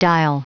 Prononciation du mot dial en anglais (fichier audio)
Prononciation du mot : dial